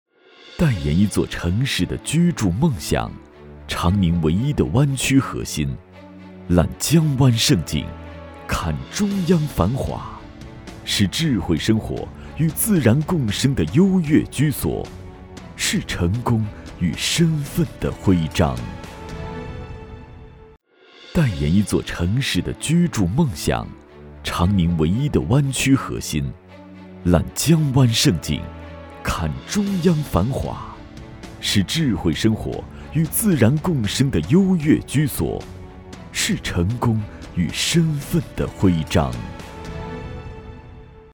国语青年大气浑厚磁性 、沉稳 、男专题片 、宣传片 、100元/分钟男S346 国语 男声 品牌专题—性冷淡风 大气浑厚磁性|沉稳